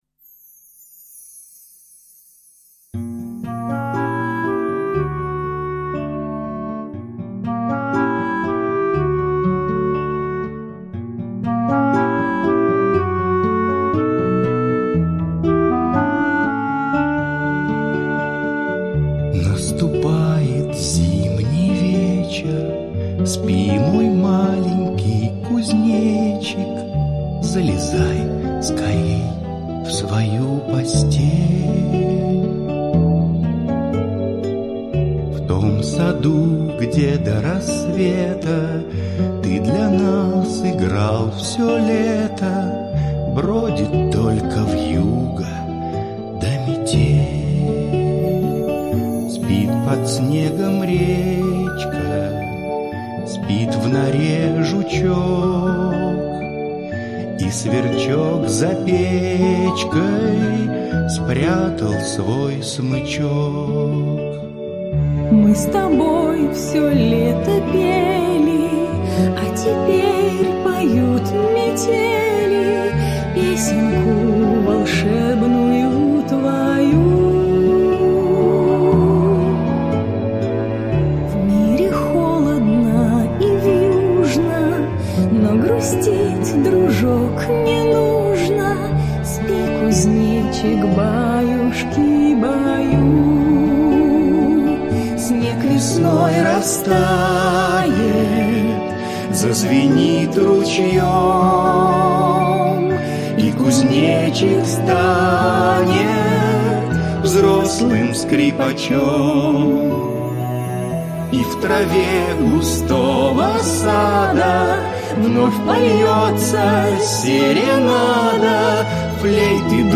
Зимняя колыбельная кузнечику- колыбельная песня.